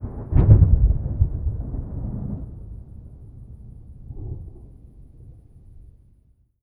THUNDER_Clap_Rumble_05_stereo.wav